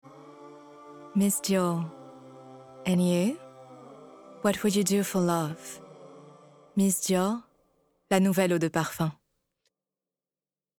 French/English voice demo - Perfume Ad
8 - 50 ans - Mezzo-soprano